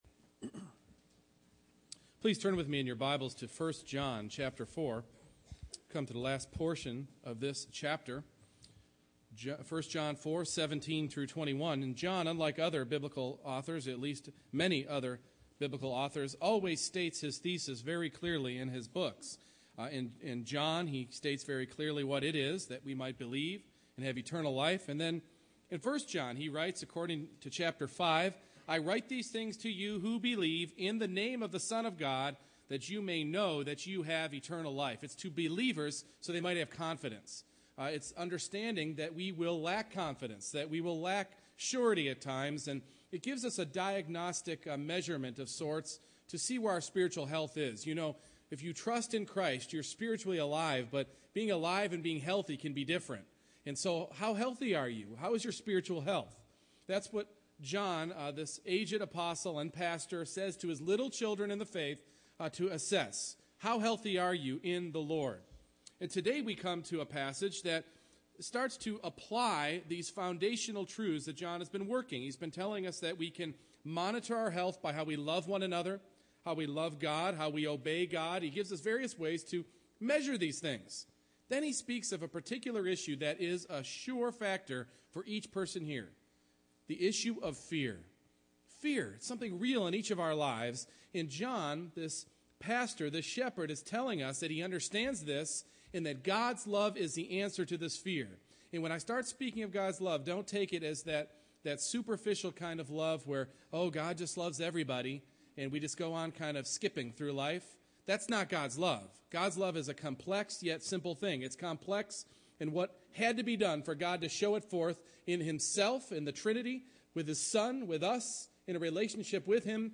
1 John 4:17-21 Service Type: Morning Worship The maturing of God's Love in your life will serve to cast out fear!